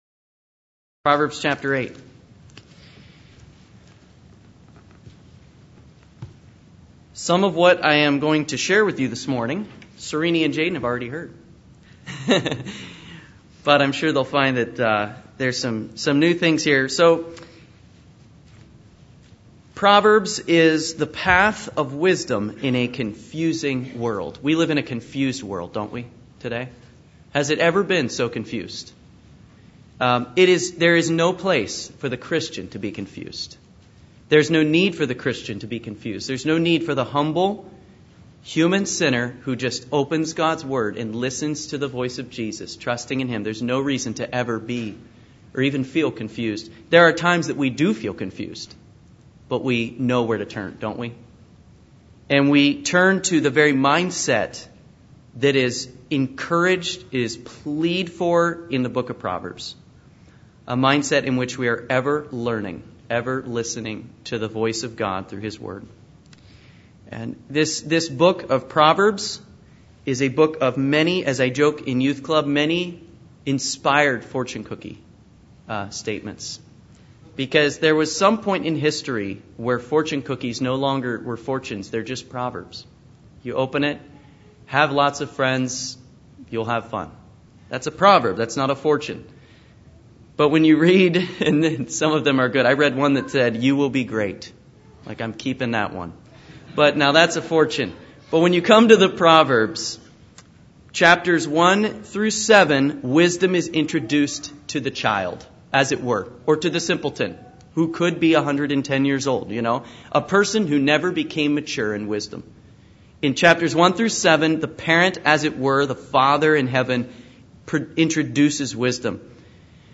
Proverbs 8 Service Type: Morning Worship « The God of All Comfort